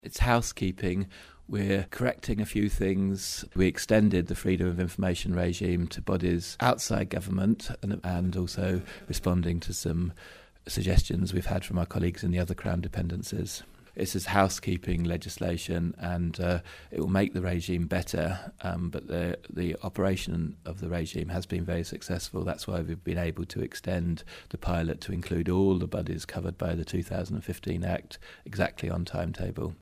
Policy and Reform Minister Chris Thomas MHK explains why the Freedom of Information Act needed amending: